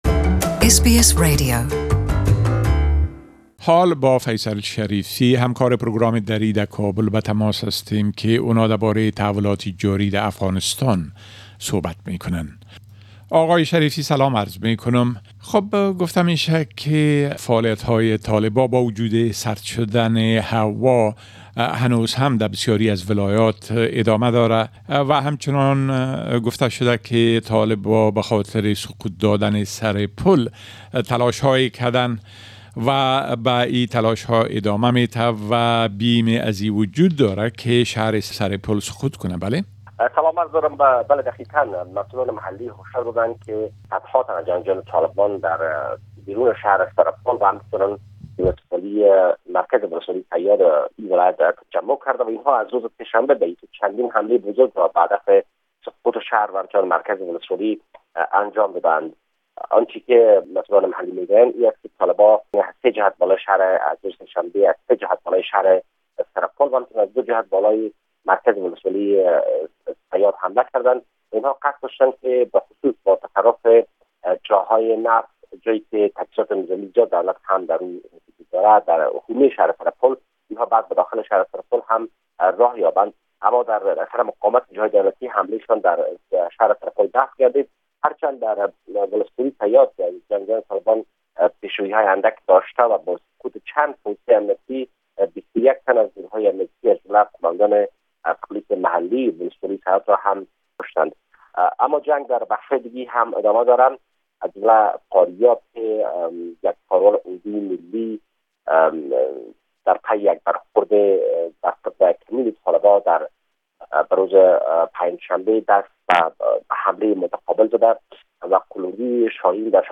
A report from our correspondent in Afghanistan